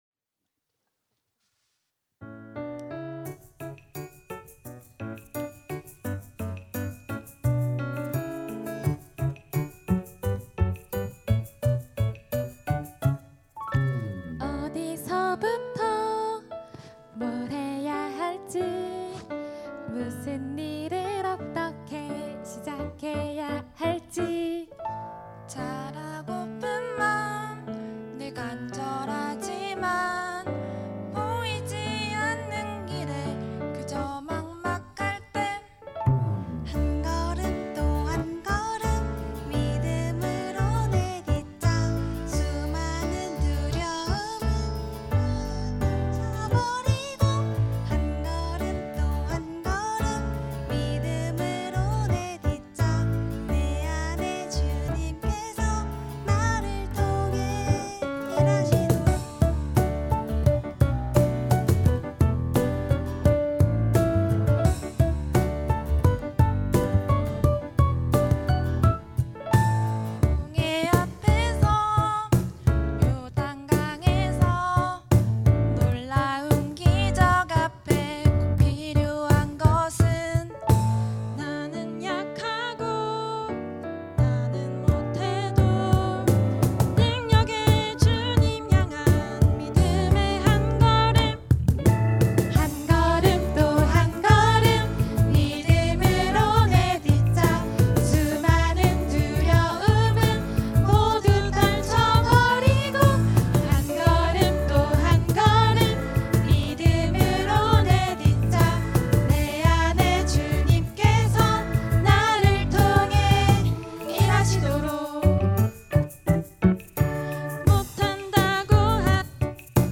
특송과 특주 - 한걸음
청년부 2022년도 4팀 더블유셀